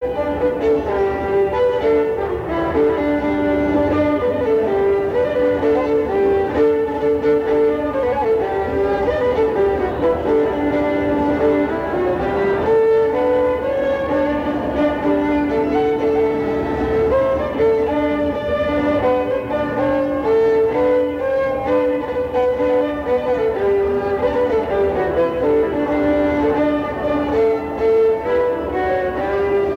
Polka - Tout va très bien madame la marquise
danse : polka
Pièce musicale inédite